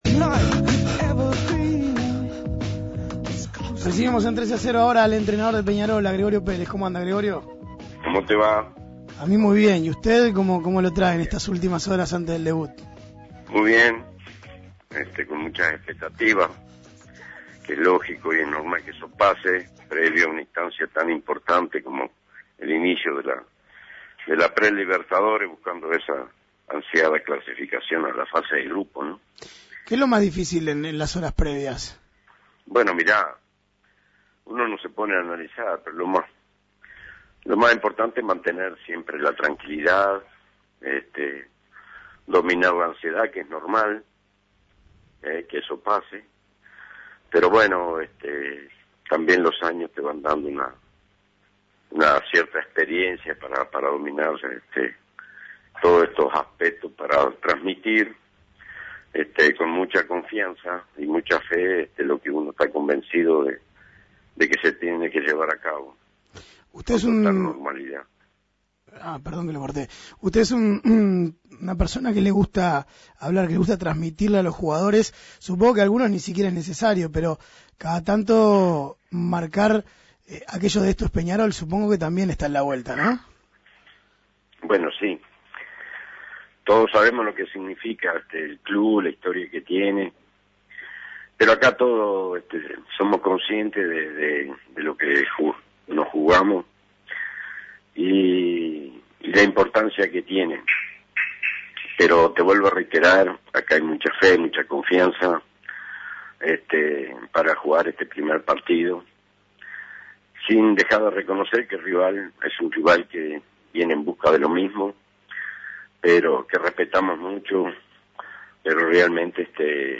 Escuche la entrevista a Gregorio Pérez